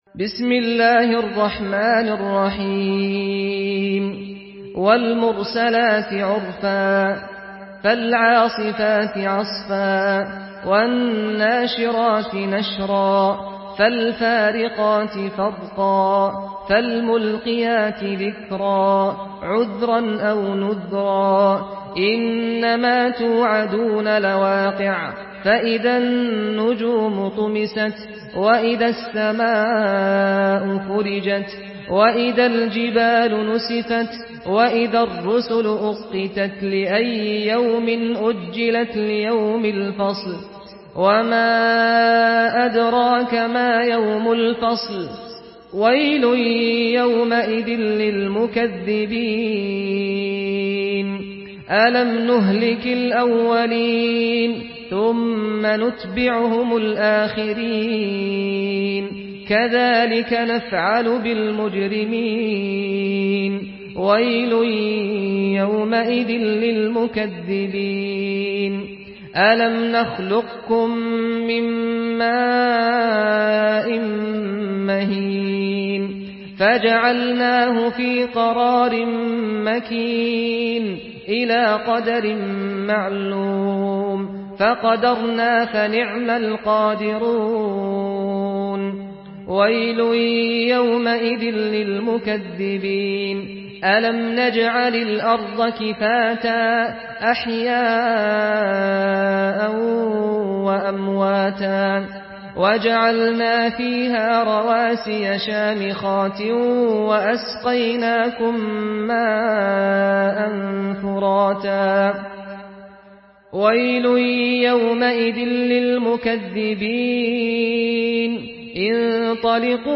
Surah আল-মুরসালাত MP3 by Saad Al-Ghamdi in Hafs An Asim narration.
Murattal Hafs An Asim